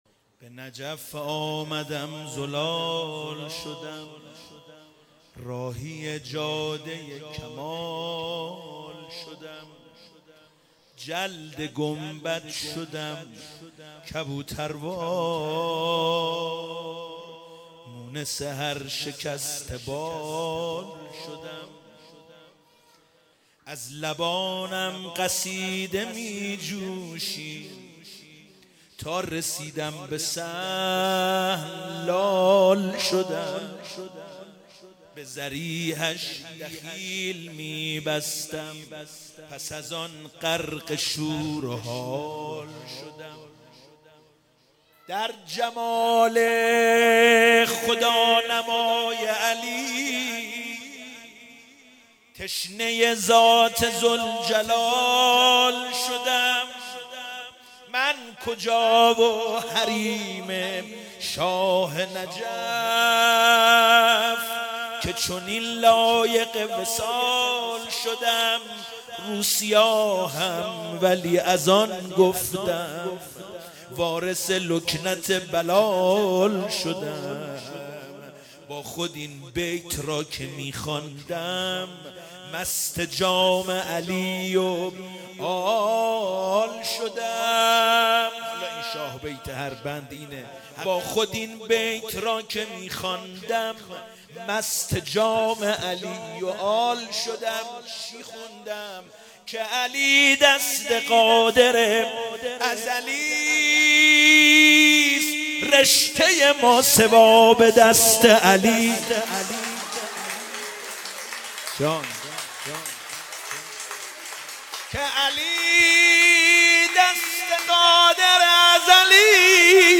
عید سعید غدیر